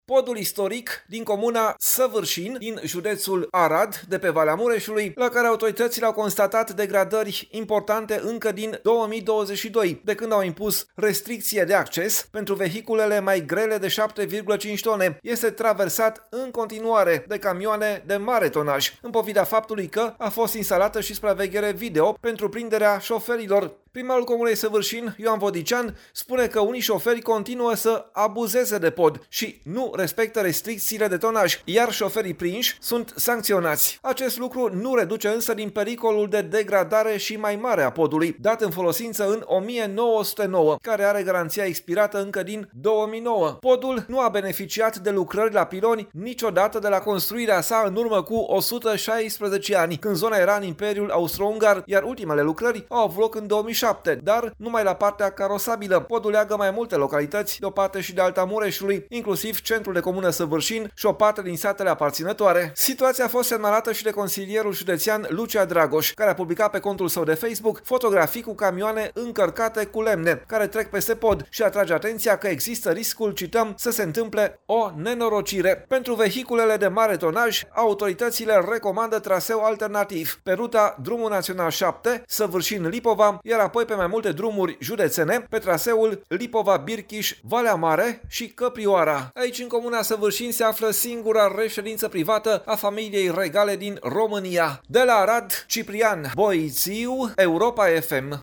23iul-11-Coresp-AR-–-pod-istoric-din-Savarsin-in-degradare.mp3